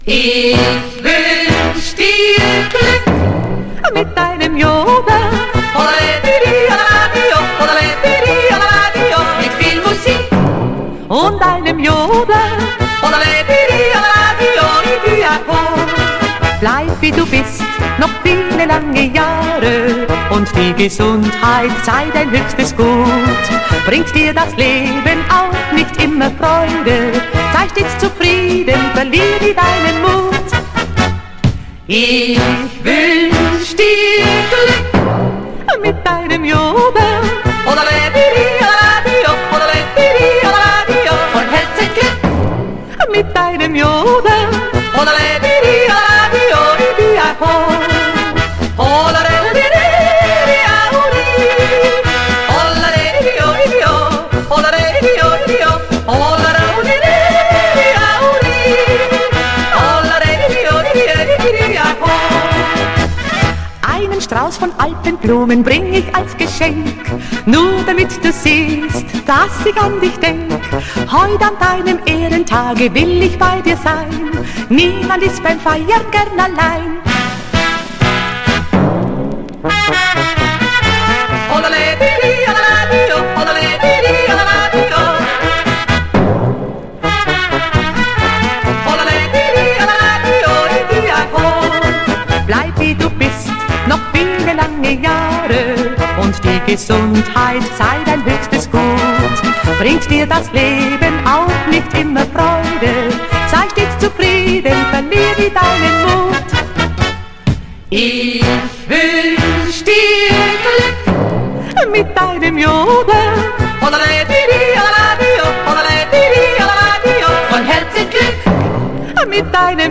recording from LP